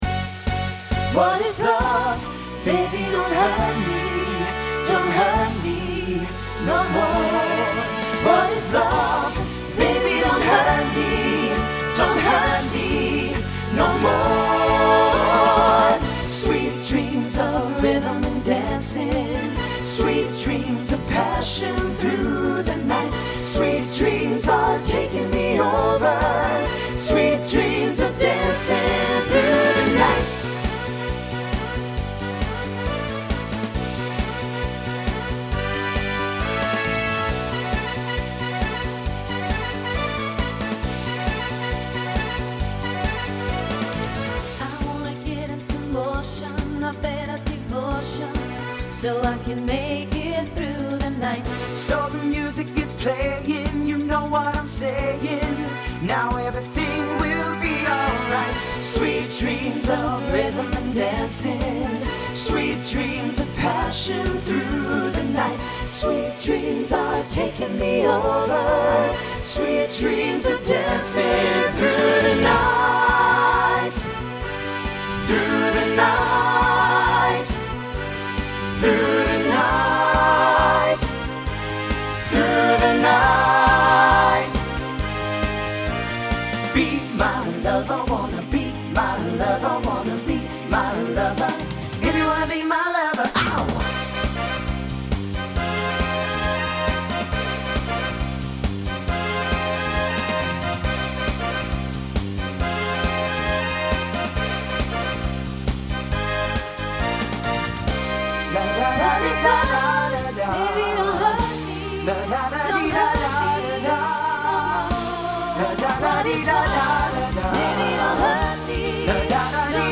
Voicing SATB Instrumental combo Genre Pop/Dance